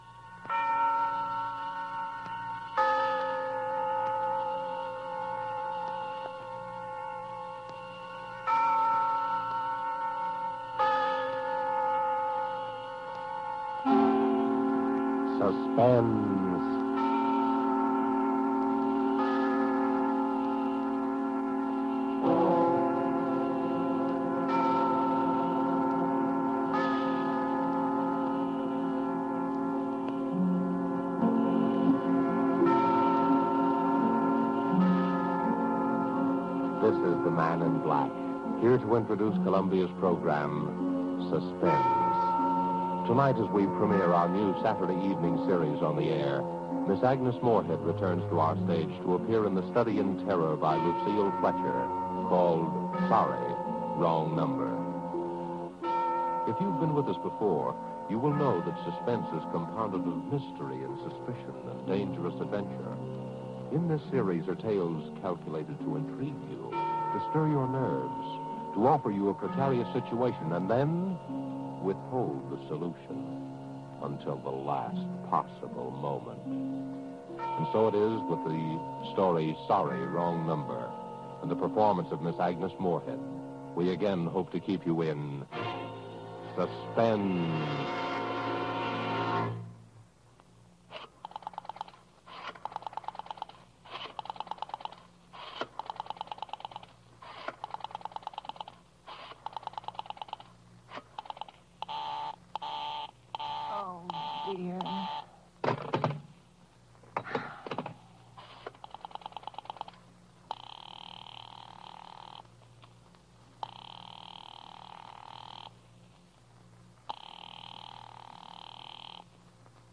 Radio Theatre: Radio Theatre: Sorry, Wrong Number
A legendary play "Sorry, Wrong Number," written by Lucille Fletcher, broadcast on May 18, 1943. Agnes Moorehead played a selfish, neurotic woman who overhears a murder being plotted via crossed phone wires and eventually realizes she is the intended victim.